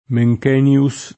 Mencken [ted. m$jkën; ingl. m$jkën] cogn. — come cogn. degli eruditi tedeschi Otto (1644-1707), Johann Burkhard (1674-1732) e Friedrich Otto (1708-54), Mencken o anche Mencke [m$j]; inoltre, latinizz. Menckenius [